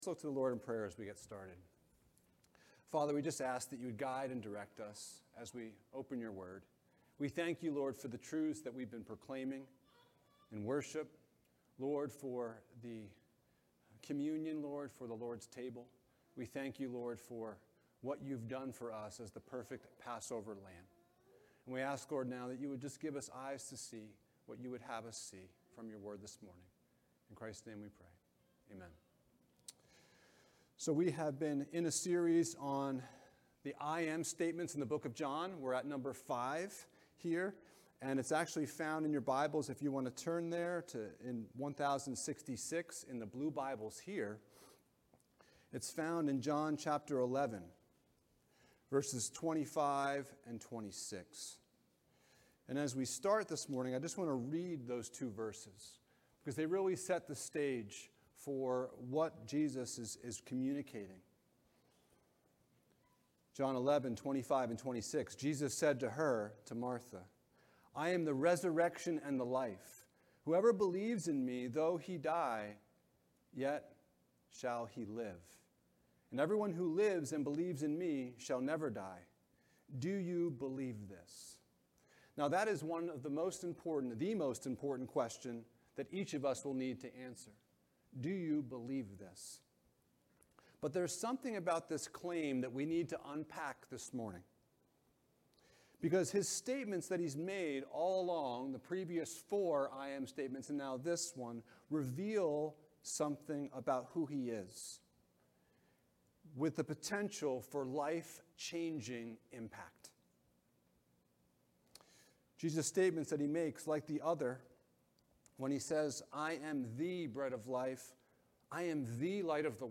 The Great I Am Passage: John 11: 17-27 Service Type: Sunday Morning « I AM the Good Shepherd I AM the Way